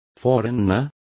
Complete with pronunciation of the translation of forerunner.